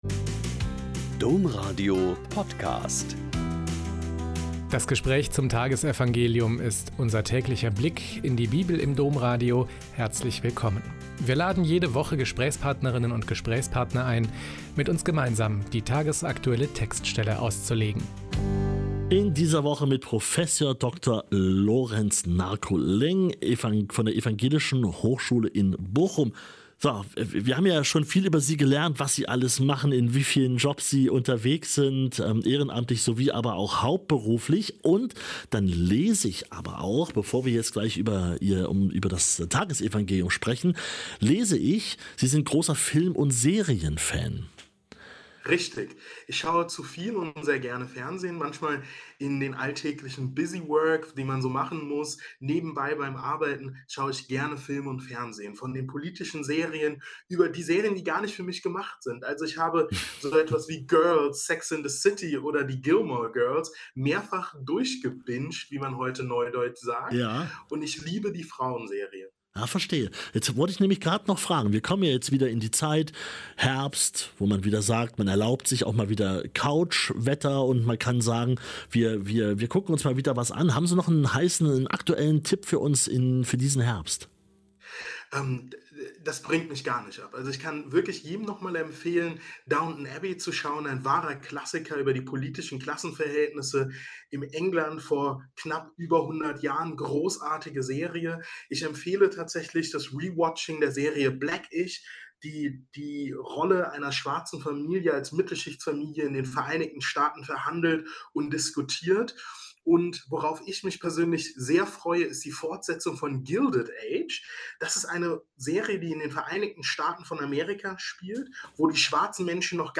Lk 4,38-44 - Gespräch